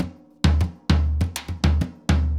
Surdo Salsa 100_1.wav